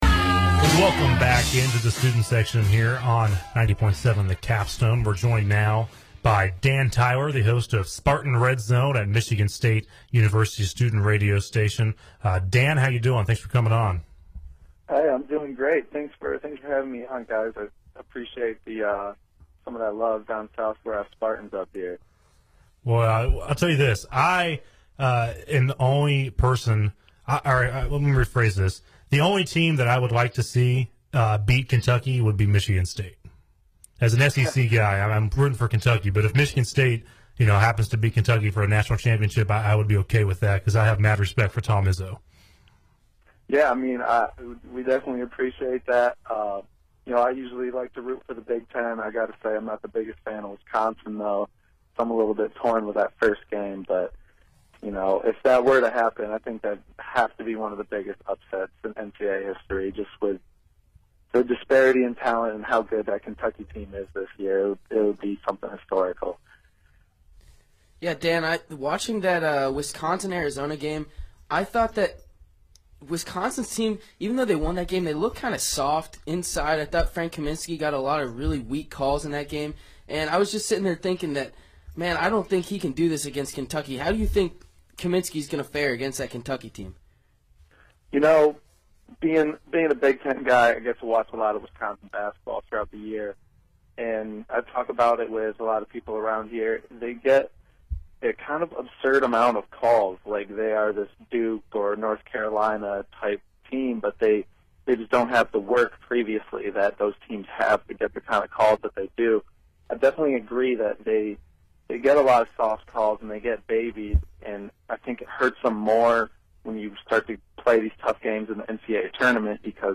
WVUA-FM's flagship sports talk show: The Student Section